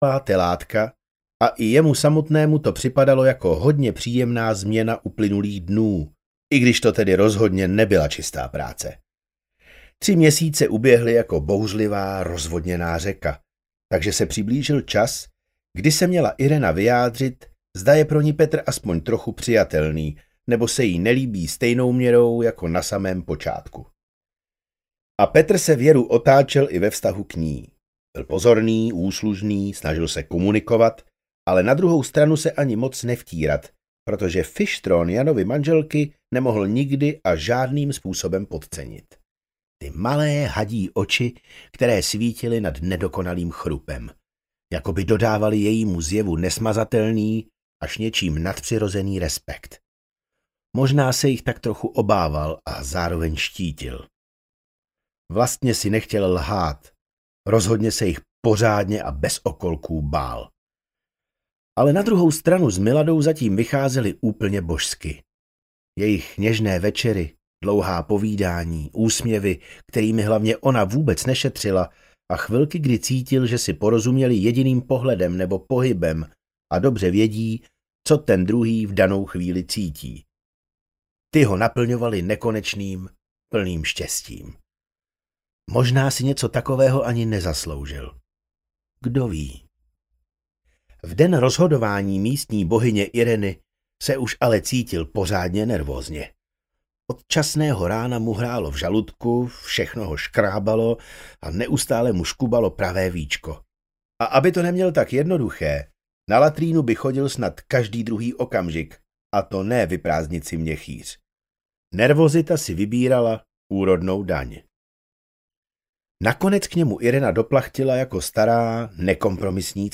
Devět křížů audiokniha
Ukázka z knihy
• InterpretKryštof Rímský